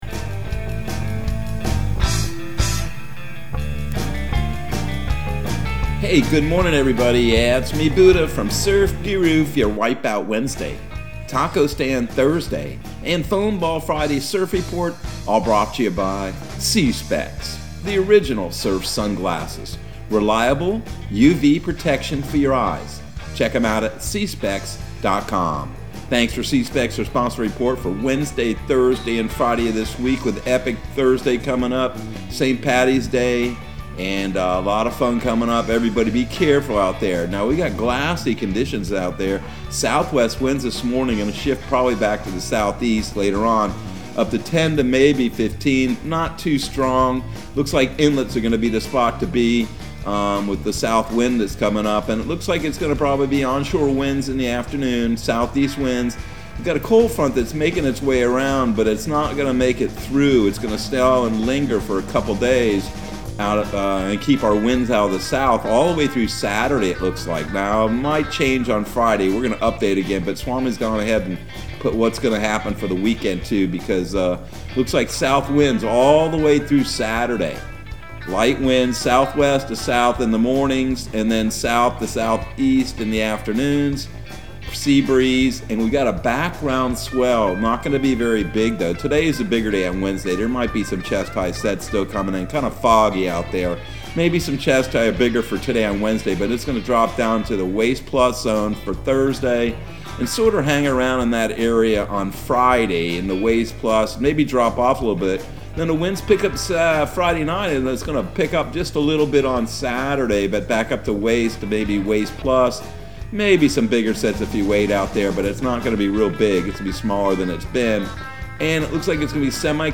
Surf Guru Surf Report and Forecast 03/16/2022 Audio surf report and surf forecast on March 16 for Central Florida and the Southeast.